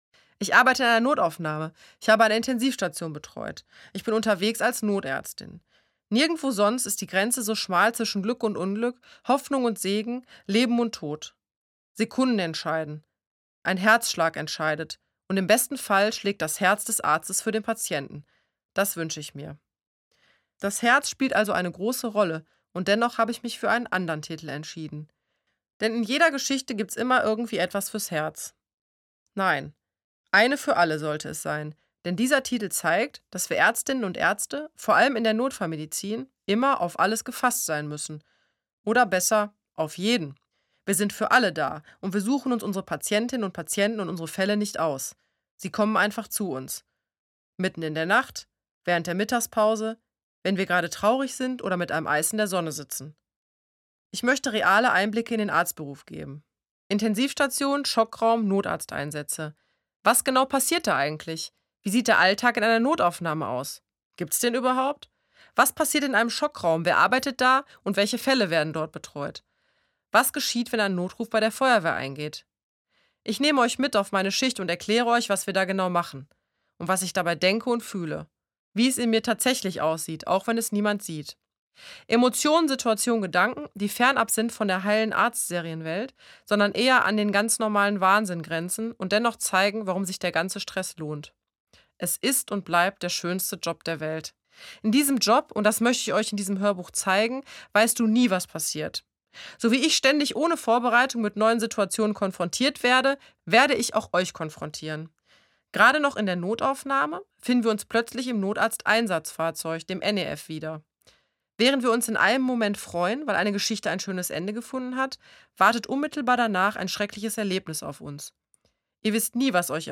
Ein authentischer Einblick in den Alltag einer Notärztin – im Hörbuch von der Autorin selbst gelesen Carola Holzner arbeitet in einer Notaufnahme und ist